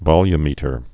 (vŏly-mētər)